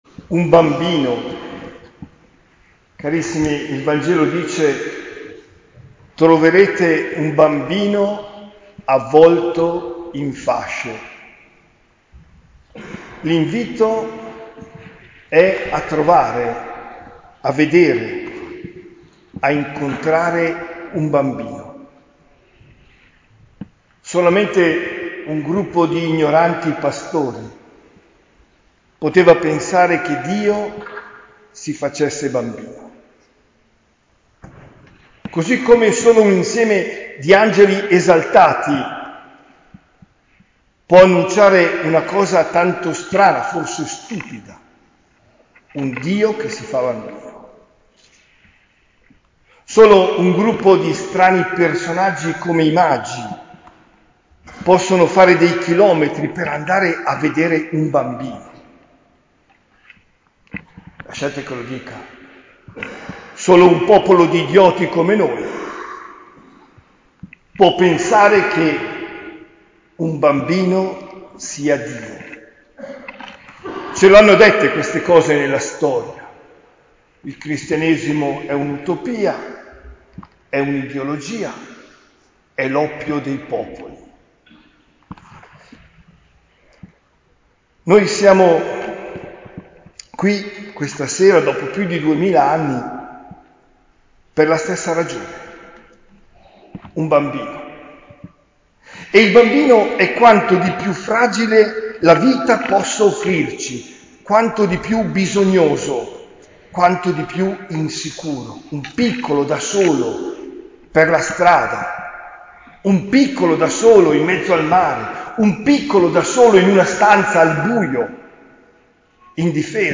OMELIA DELLA NOTTE DI NATALE 2022
omelia-notte-natale.mp3